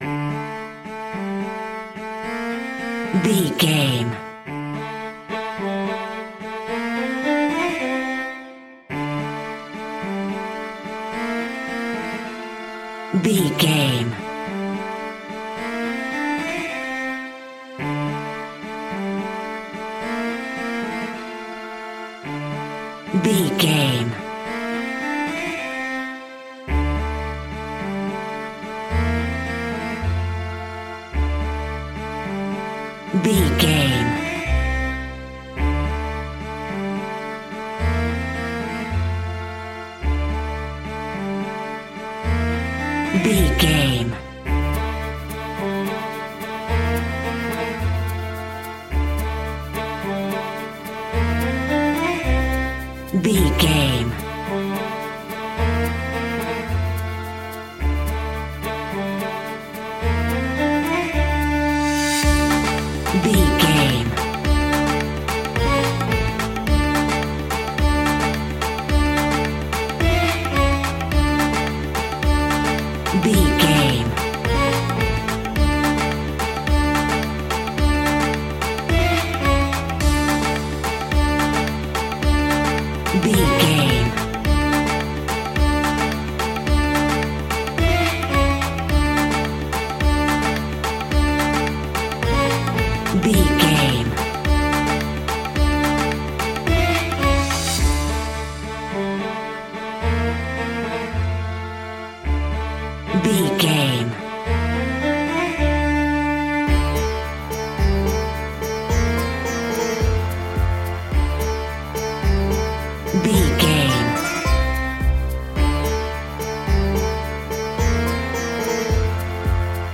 Aeolian/Minor
D
percussion
congas
bongos
djembe
kalimba
marimba